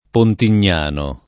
[ pontin’n’ # no ]